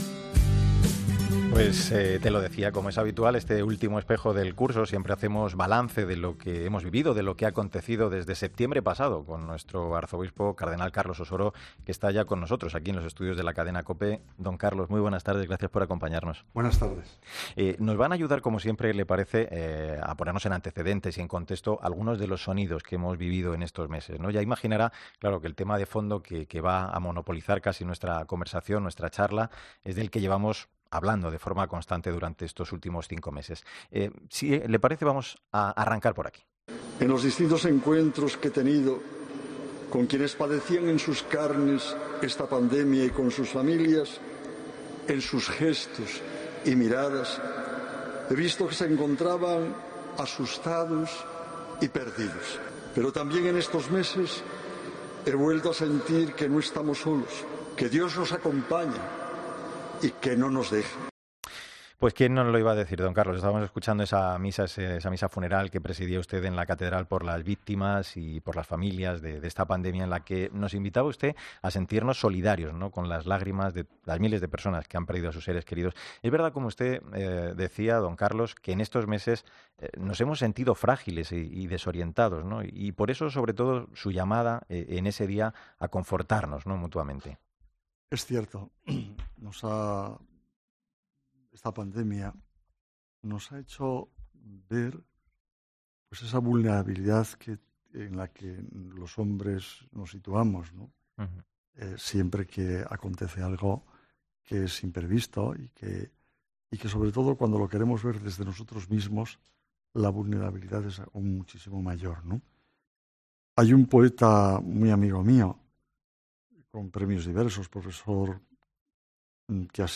El arzobispo de Madrid ha analizado en "El Espejo de Madrid" de COPE lo que ha supuesto este año para la Iglesia de la archidiócesis